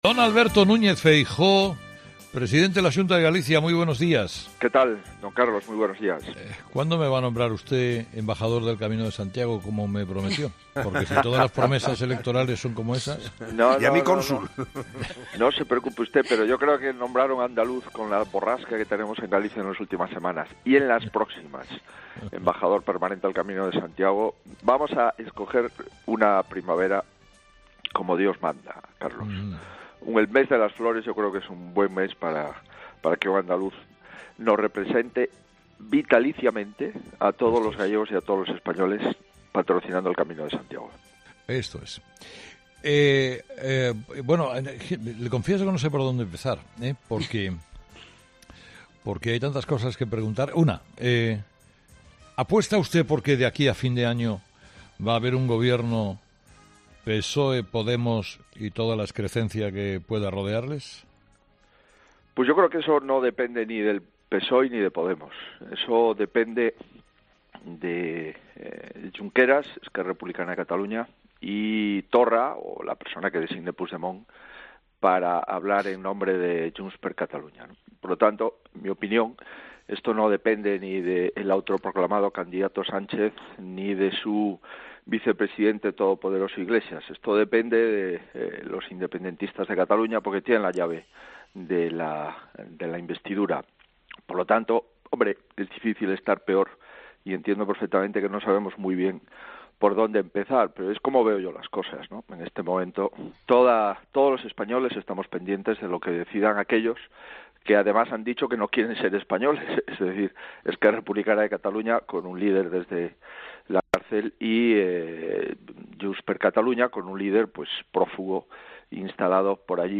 AUDIO: Escucha ahora al presidente de la Xunta, Alberto Núñez Feijóo, emitido l 22 de noviembre de 2019 en 'Herrera en COPE'.